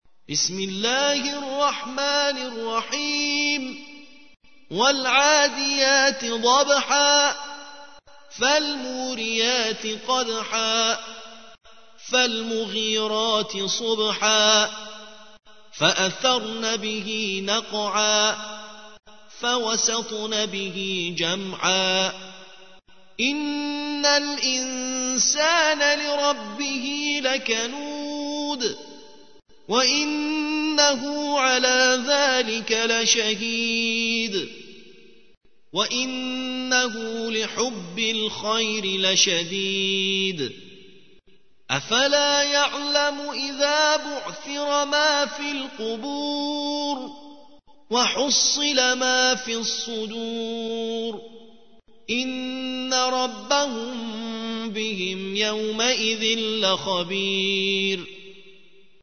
مجموعه منتخبی از قرائت های قرانی